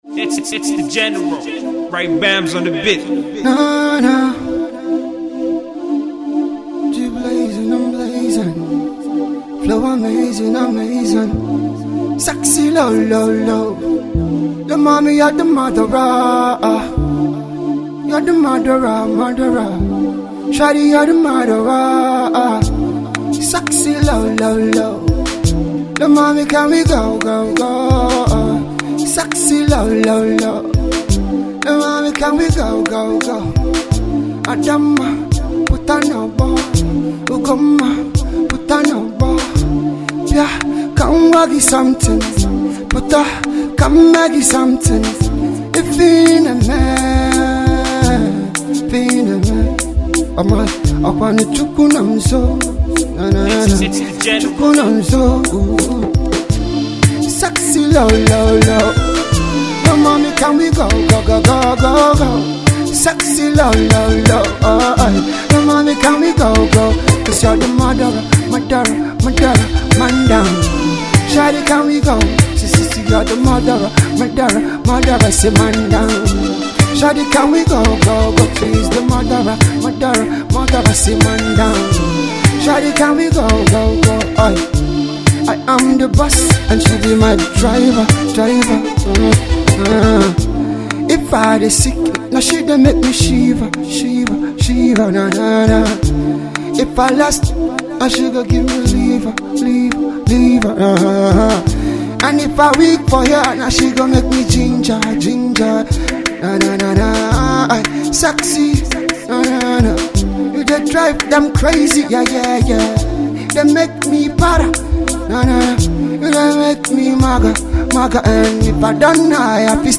new Afro banger